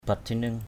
/ba-tʰi-nɯŋ/ (d.) tên một thủ đô cũ Champa = nom d’une ancienne capitale